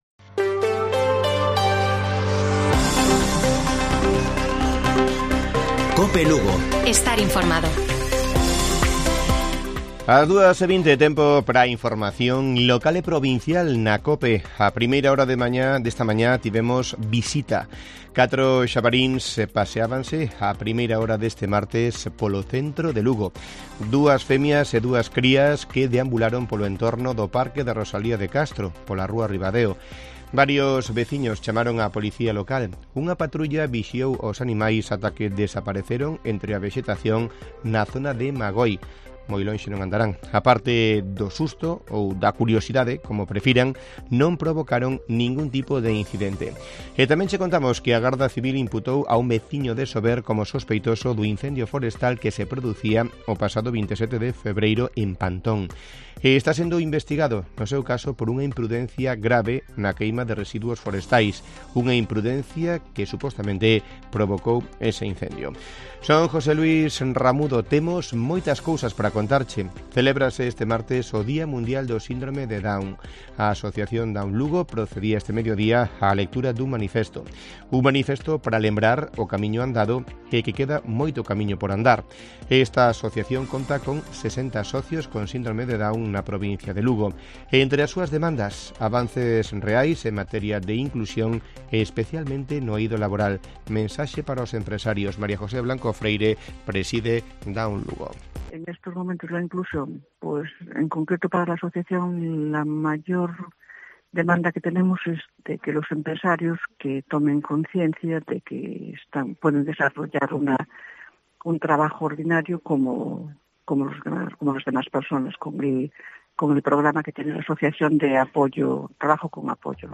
Informativo Mediodía de Cope Lugo. 21 de marzo. 14:20 horas